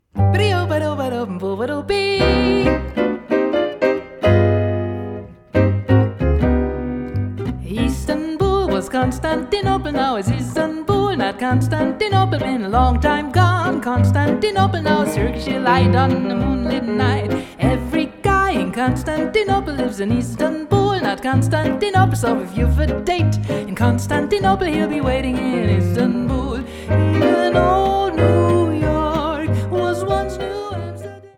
Studio-Recording